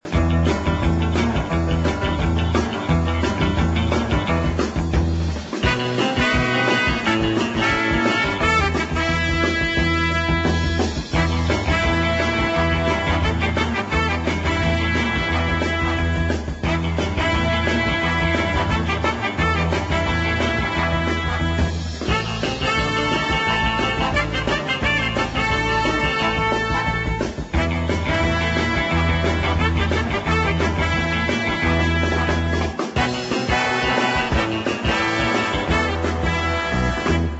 1962 exciting fast instr.